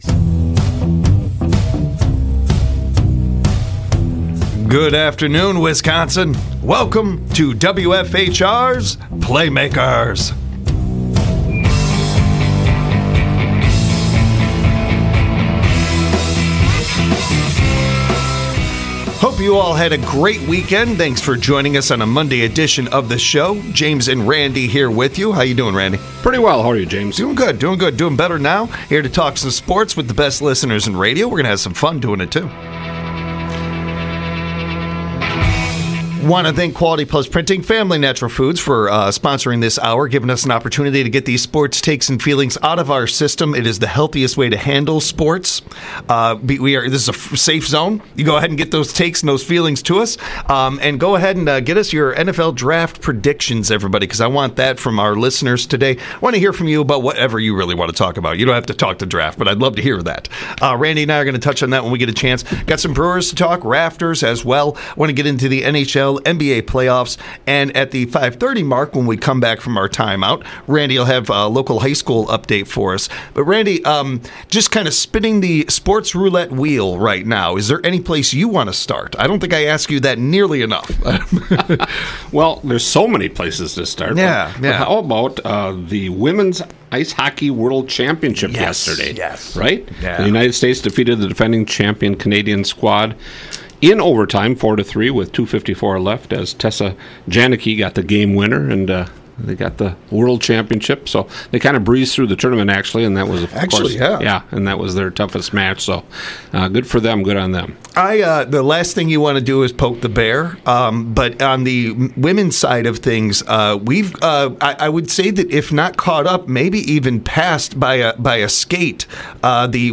Back when this first started the live sports call in hour was called 'Coffee & Sports'.